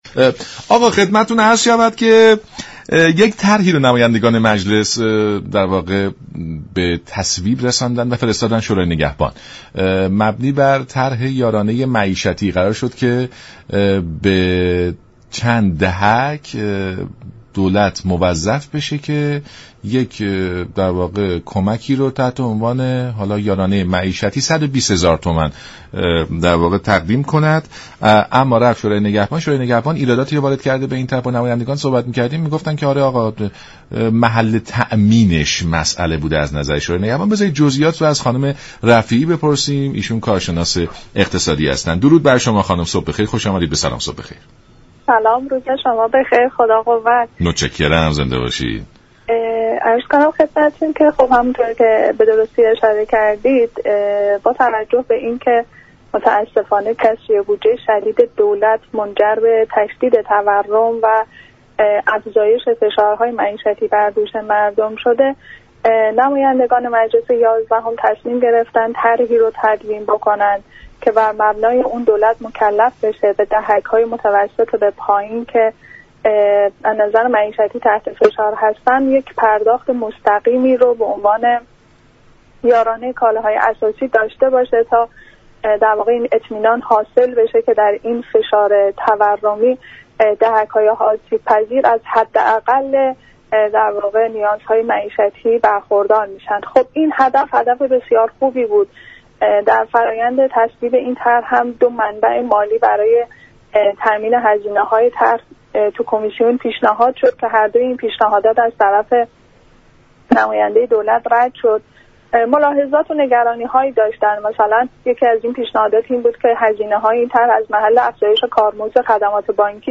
كارشناس مسائل اقتصادی گفت: مجلس دولت را موظف كرده منابع مالی طرح را از محل فروش سهام خود در بانك ملت، پالایشگاه تهران، شركت ملی مس، سهام سود مباركه و شركت مخابرات تامین كند.